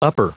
Prononciation du mot upper en anglais (fichier audio)
Prononciation du mot : upper